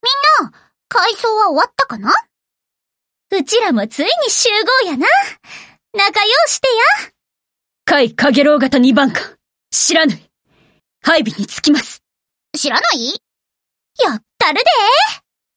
Here are the voice lines played upon completion of certain quests.